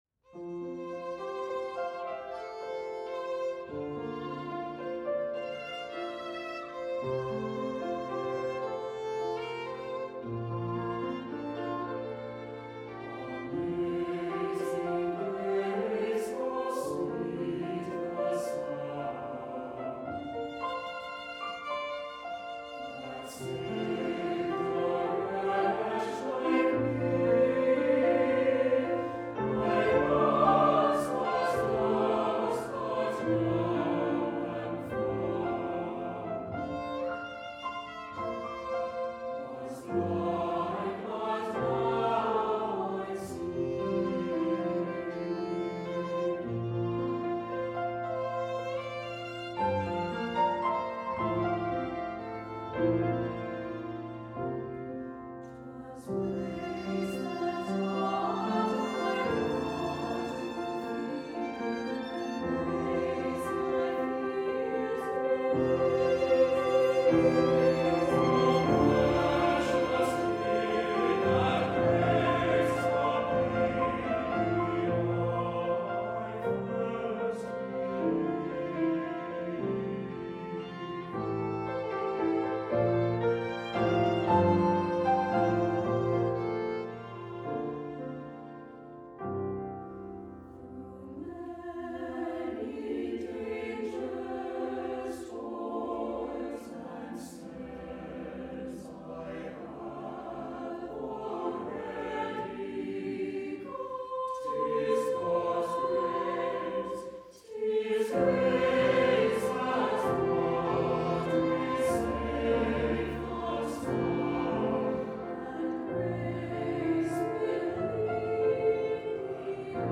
Composer: American Folk Tune
Voicing: SATB and Piano